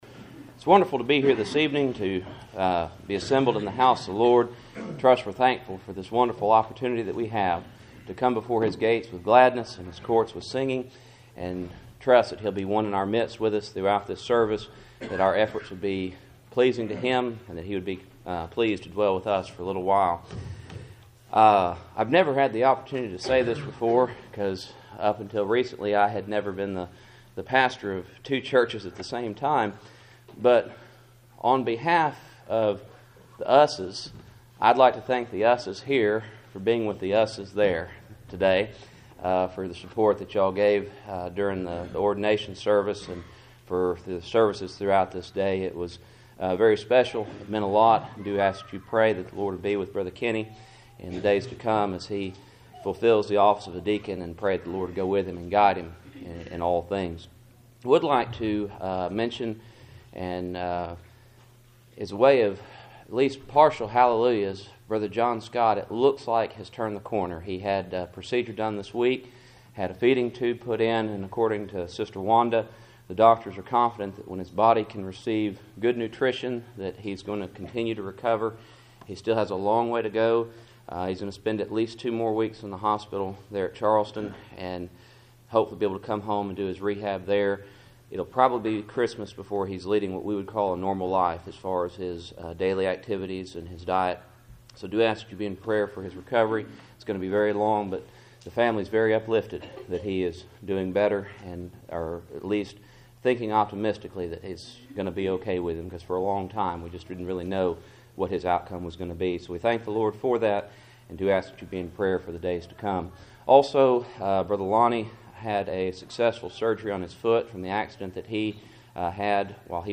Psalm 73:0 Service Type: Cool Springs PBC Sunday Evening %todo_render% « II Timothy 3:8-13